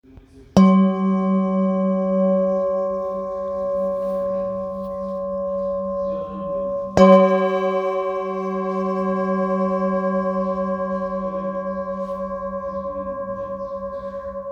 Singing Bowl, Buddhist Hand Beaten, with Fine Etching Carving of Samadhi, Select Accessories, 20 by 20 cm,
Material Seven Bronze Metal